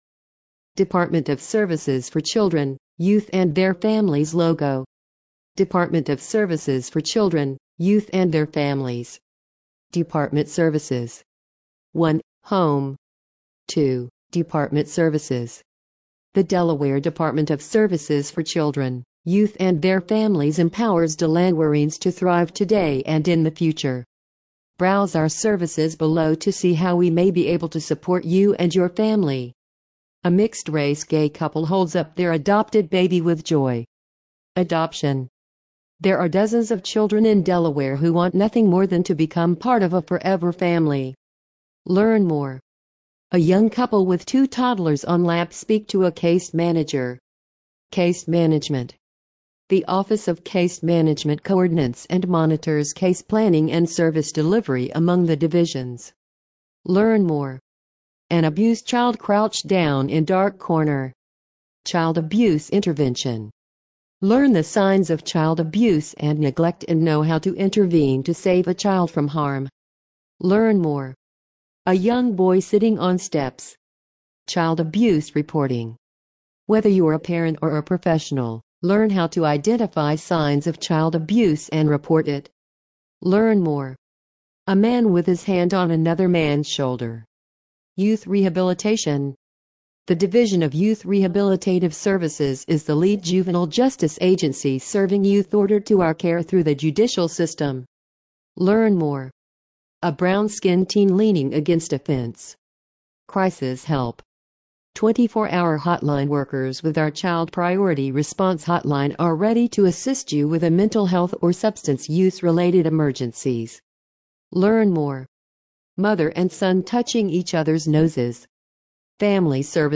Listen to this page using ReadSpeaker